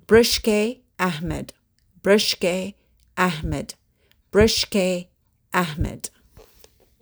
(Avec prononciation audio)